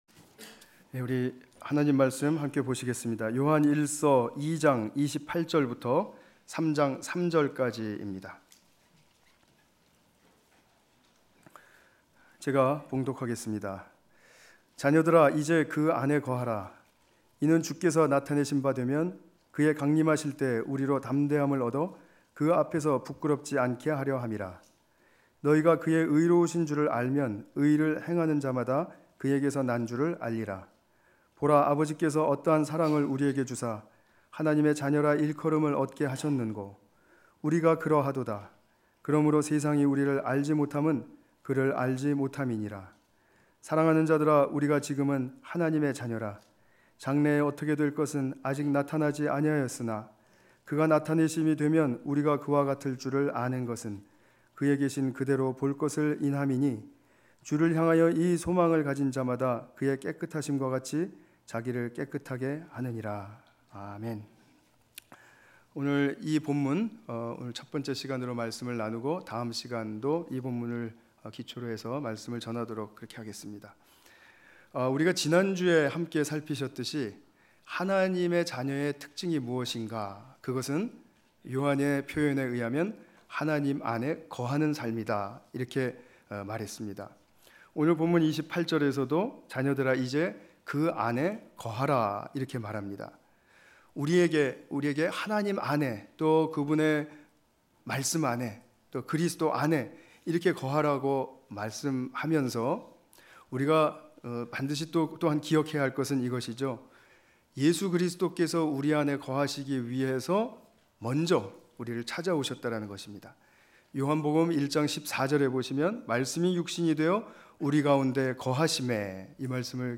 요한일서 2장28절-3장3절 관련 Tagged with 주일예배